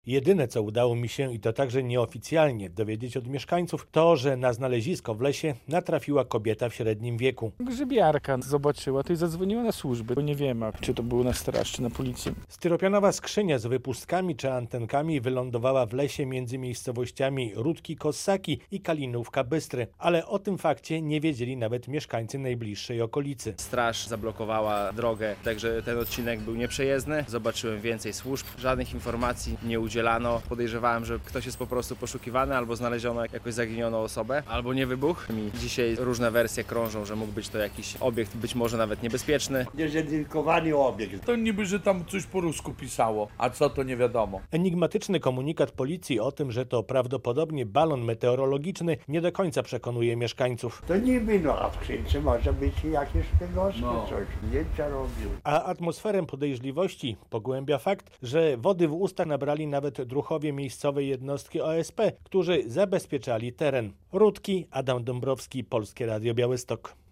Mieszkańcy gminy Rutki zastanawiają się, co wylądowało w lesie w pobliżu ich miejscowości - relacja